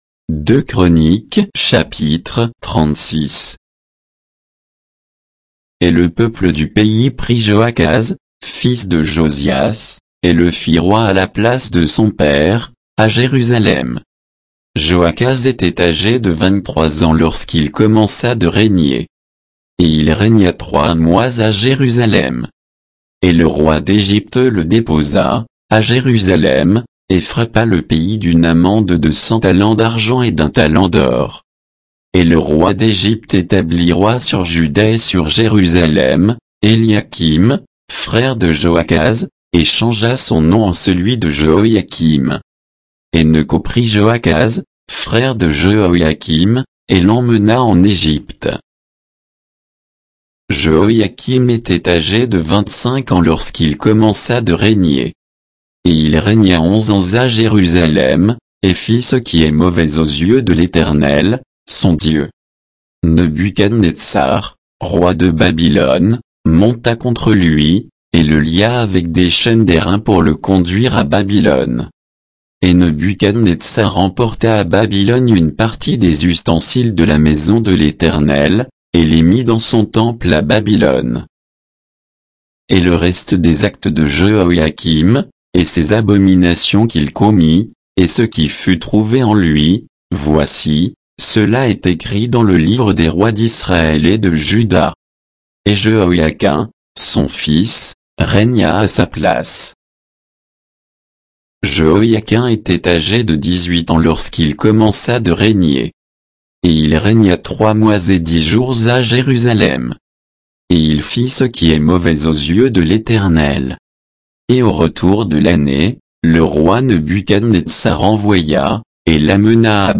Bible_2_Chroniques_36_(texte_uniquement).mp3